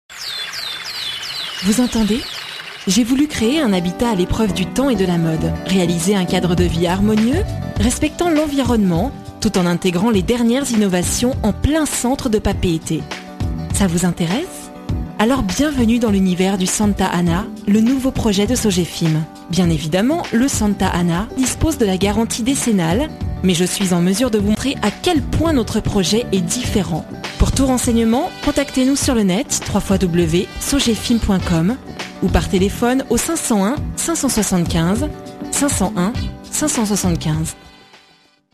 Female
Approachable, Assured, Authoritative, Bright, Bubbly, Character, Children, Confident, Conversational, Corporate, Deep, Energetic, Engaging, Friendly, Gravitas, Natural, Reassuring, Smooth, Soft, Versatile, Warm, Witty
Microphone: TLM Neumann 103